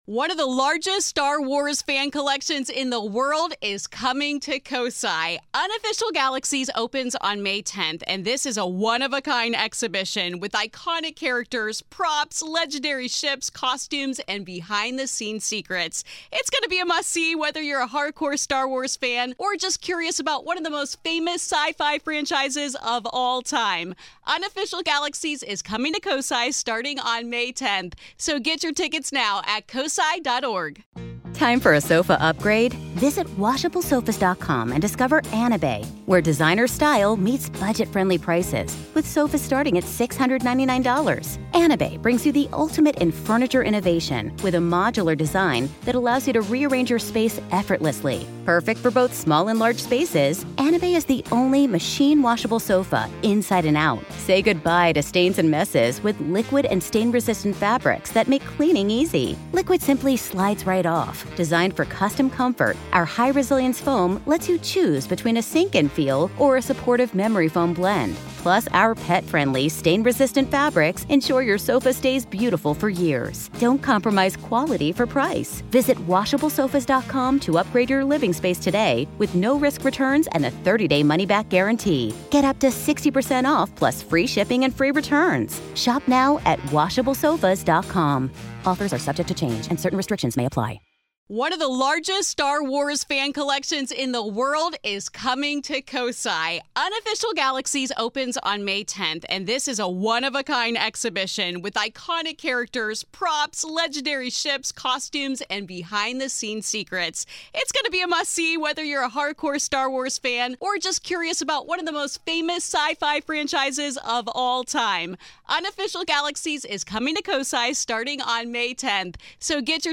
PART 2 - AVAILABLE TO GRAVE KEEPERS ONLY - LISTEN HERE In part two of our interview, available only to Grave Keepers , we discuss: Is there ever a way to truly eradicate a house of ghosts if the spirit refuses to leave?